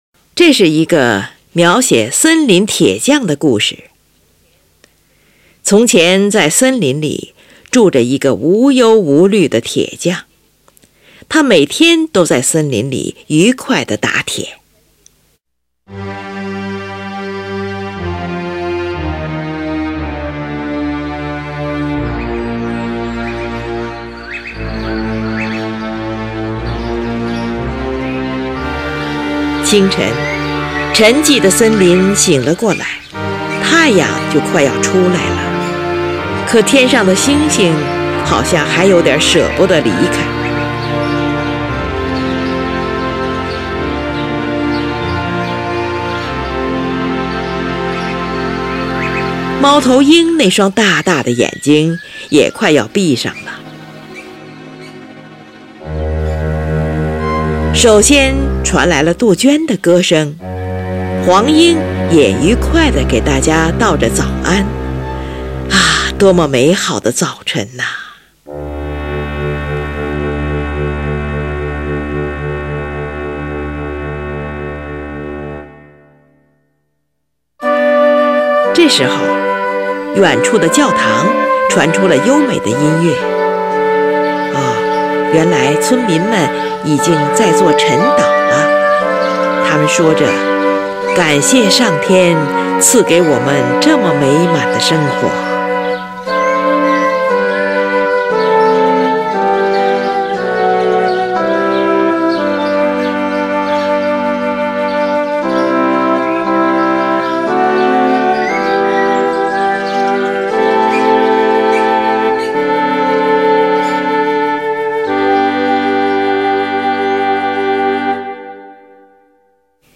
管弦乐曲
乐曲分为五段，以造型性表现手法写成。
主题旋律悠长平稳，描绘了宁静的森林夜景。
主题中运用快速的十六分音符和跳进音程的曲调，描绘了小溪潺潺的流水声和杜鹃的啼鸣；三拍子轻快的节奏，烘托出森林的幽静气氛。